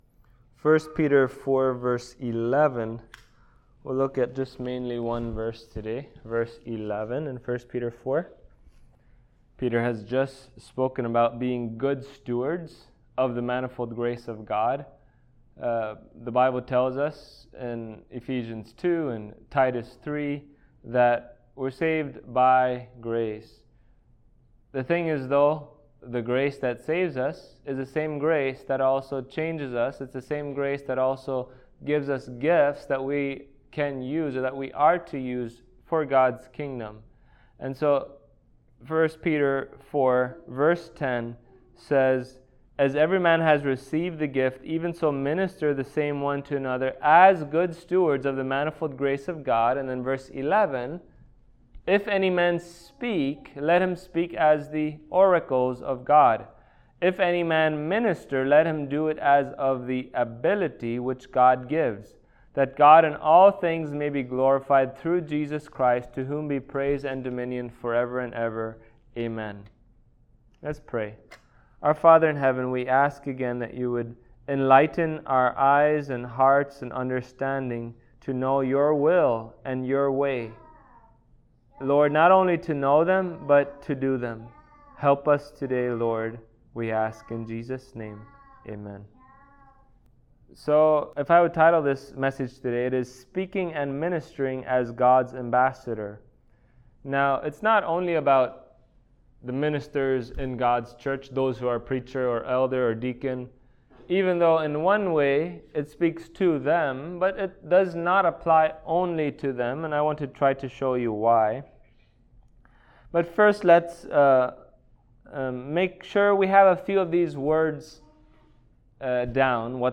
Passage: 1 Peter Service Type: Sunday Morning